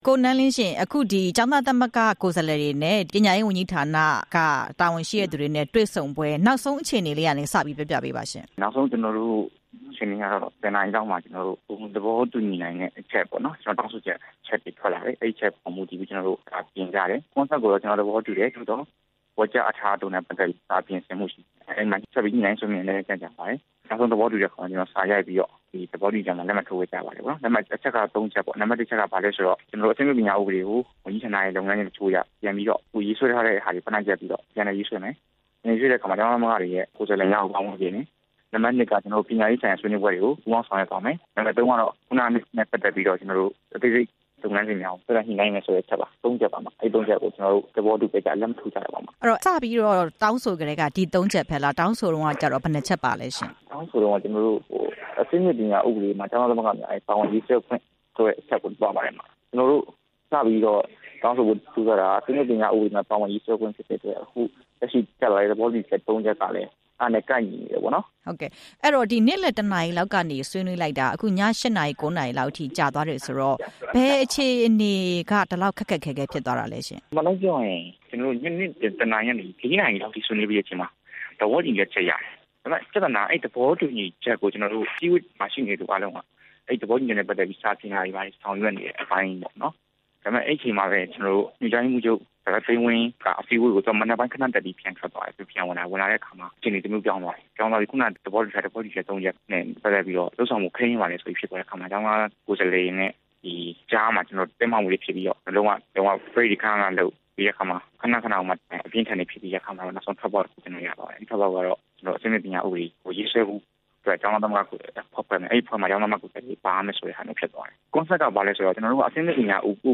အဆင့်မြင့်ပညာရေးဥပဒေကိစ္စ ဆွေးနွေးမှု မေးမြန်းချက်